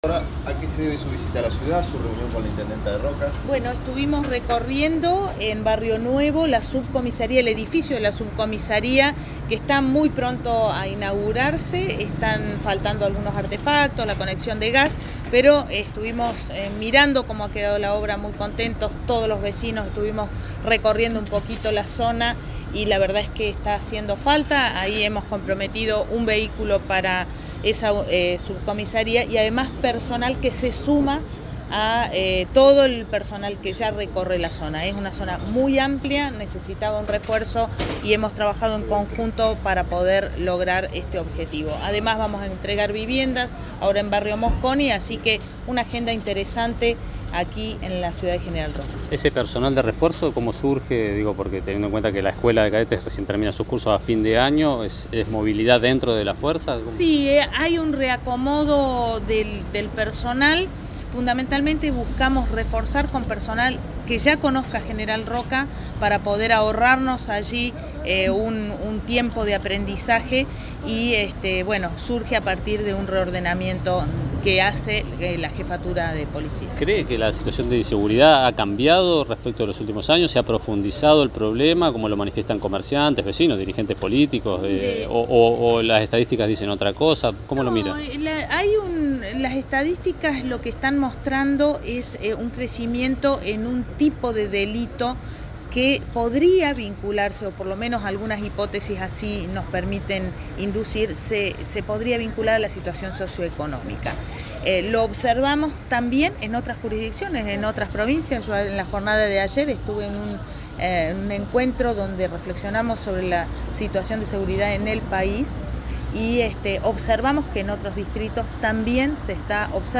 La mandataria visitó a la jefa comunal y tras recorrer la obra de la dependencia policial, ambas dialogaron con la prensa.
Escuchá a Arabela Carreras